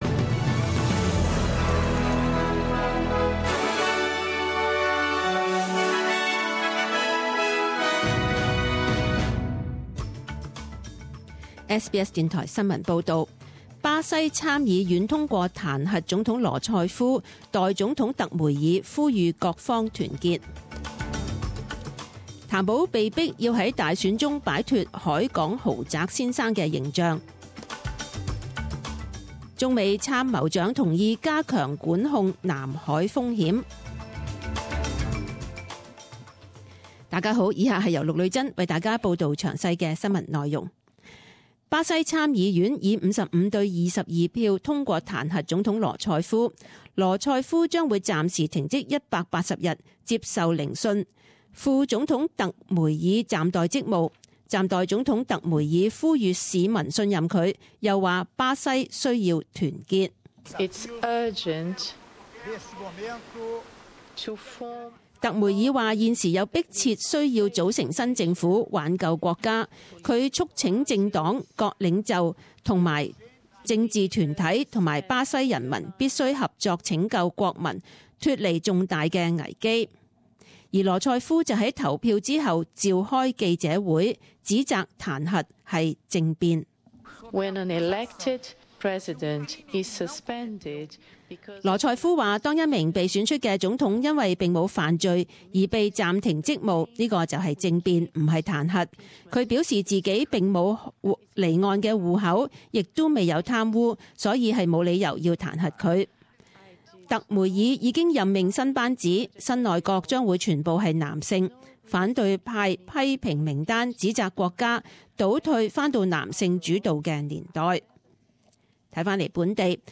十點鐘新聞報導 （五月十三日）